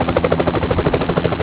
helicopt01
helicopter interior
helicopt01.wav